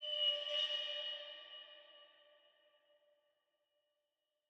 ambience_arena_creak-003.wav